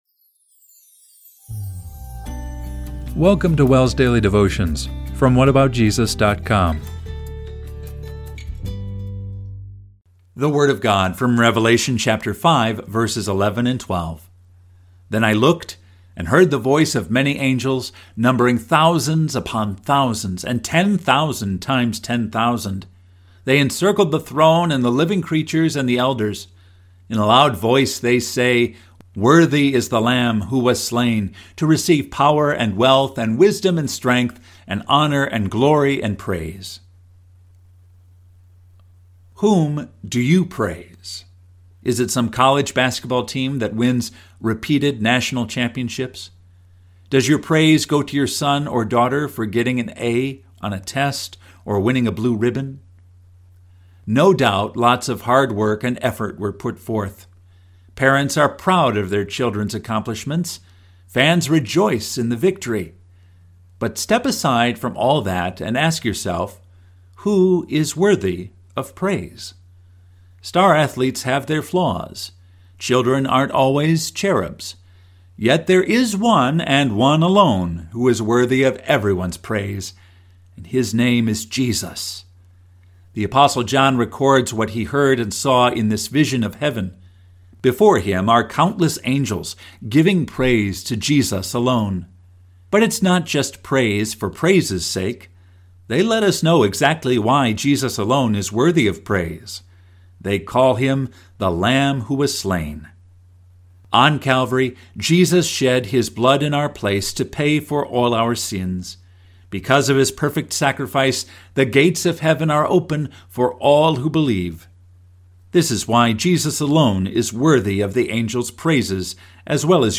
Daily Devotion – June 7, 2025